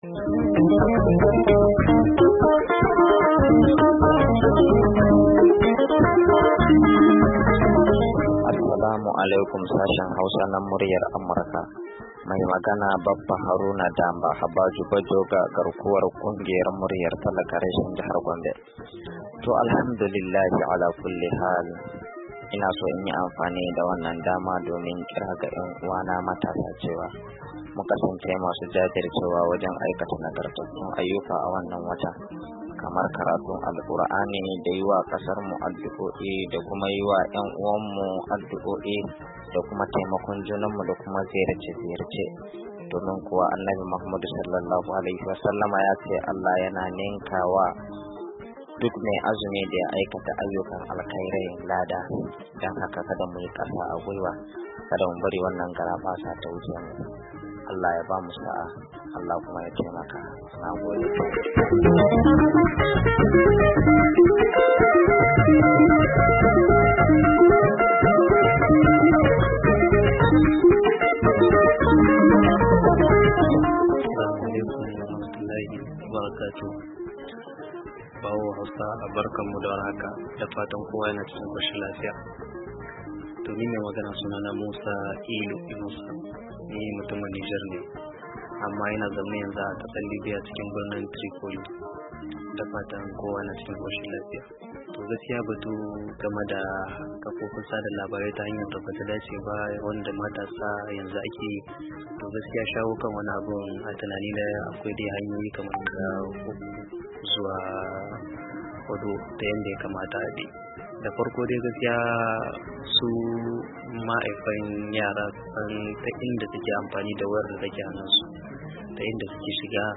Sakonnin Ra'ayoyin Masu Sauraro Ta Kafar WhatsApp